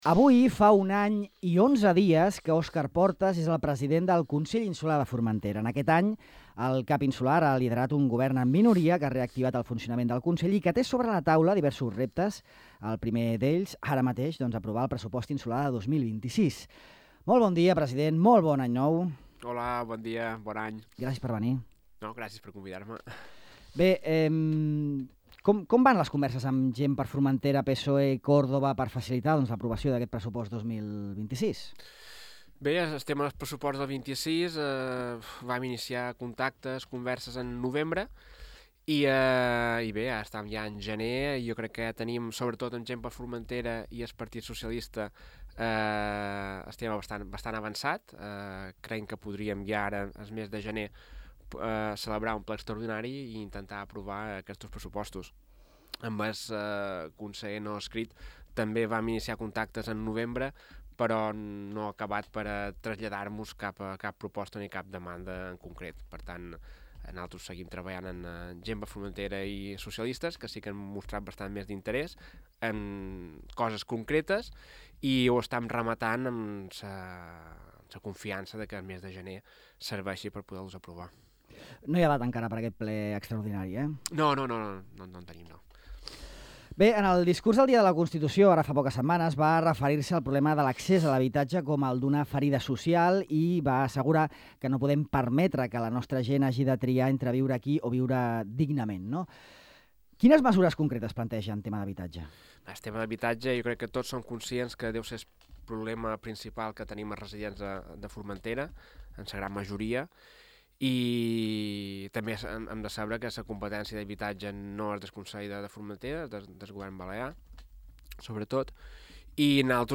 El president del Consell, Óscar Portas, ha declarat a Ràdio Illa que el 2026 ha de ser l’any per consolidar la regulació del fondeig a l’estany des Peix. En la primera entrevista de l’any, Portas ha anunciat dos novetats amb relació a aquesta llacuna protegida.